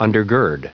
Prononciation du mot undergird en anglais (fichier audio)
Prononciation du mot : undergird